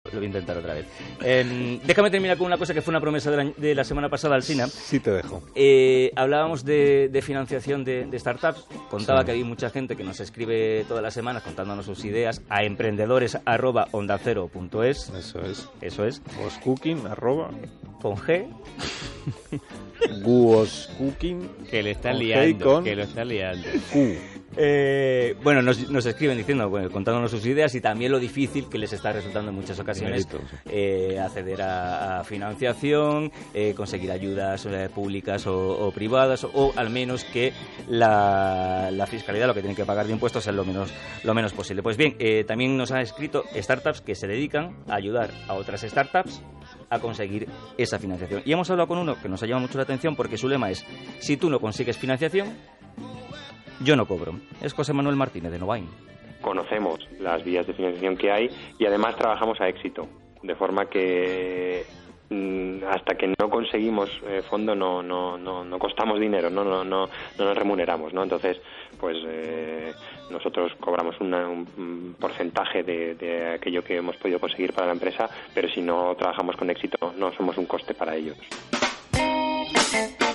Audio de la intervención de NOVAÍN Consultores en el programa de Onda Cero, La Brújula, de Carlos Alsina, en su sección de emprendedores «What´s Cooking»